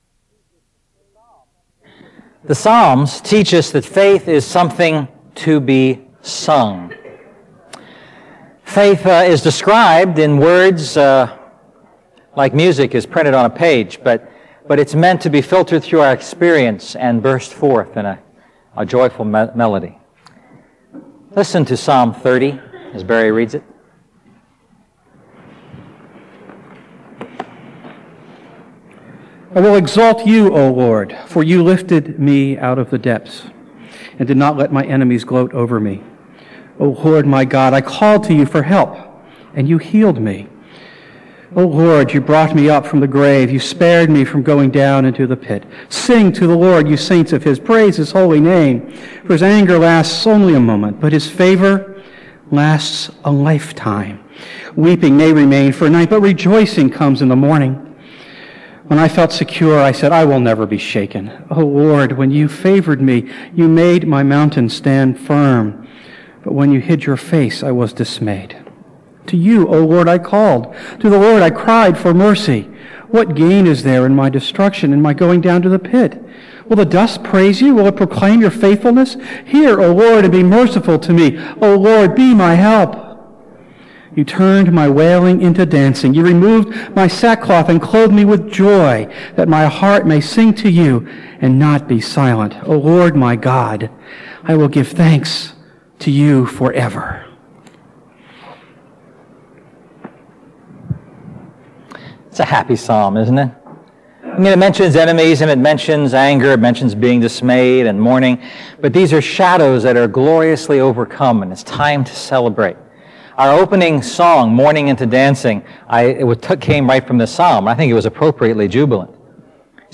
A message from the series "A New Song."